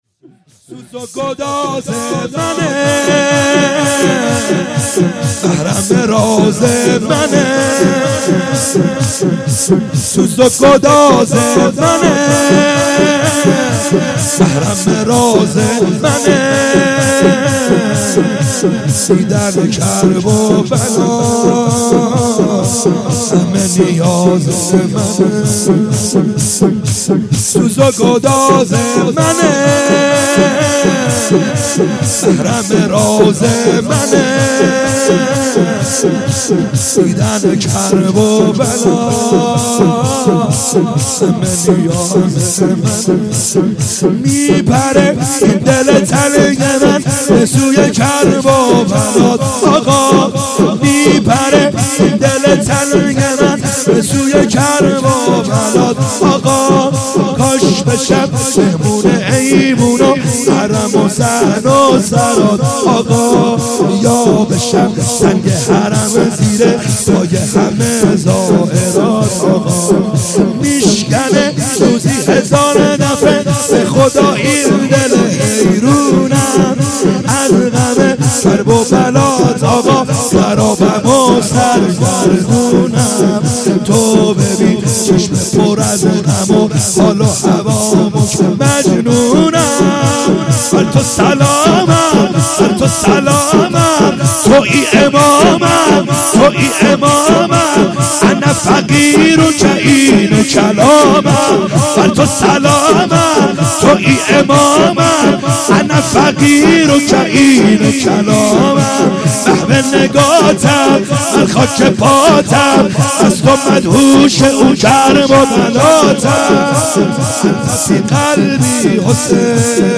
خیمه گاه - بیرق معظم محبین حضرت صاحب الزمان(عج) - شور | سوز و گداز منه